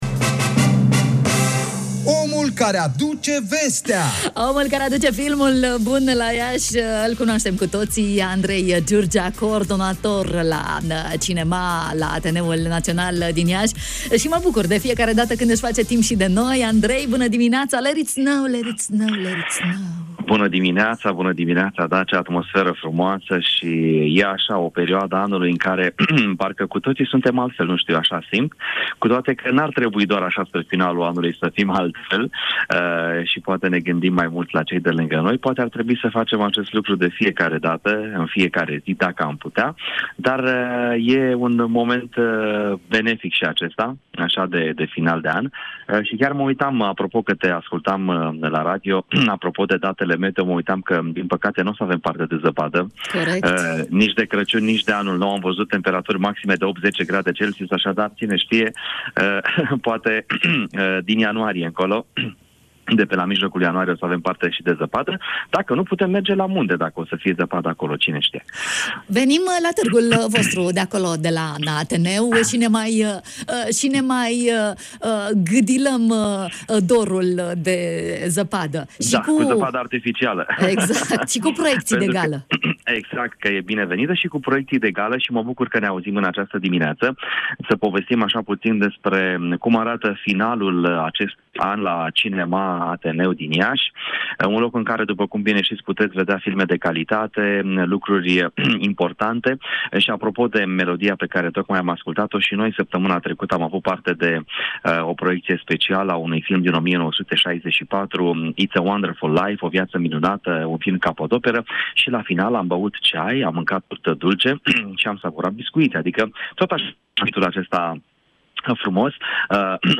Evenimentele de final de an, de la Cinema Ateneu.